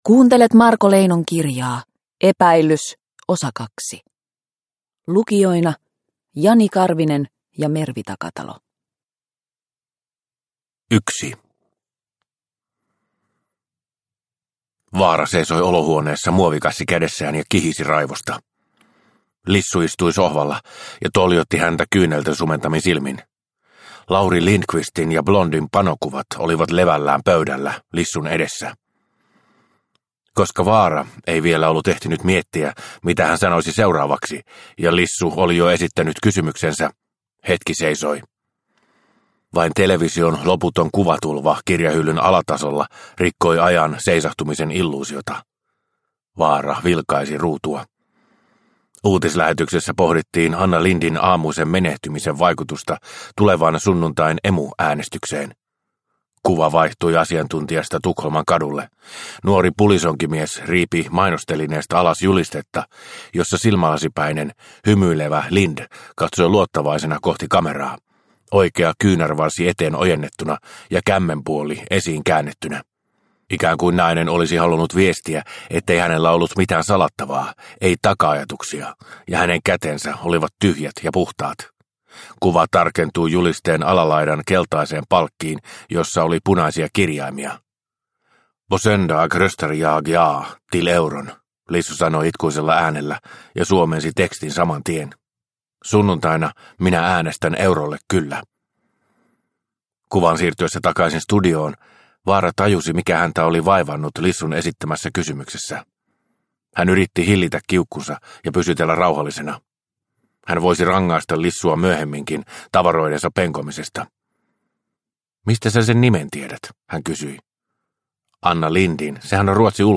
Epäilys – Ljudbok – Laddas ner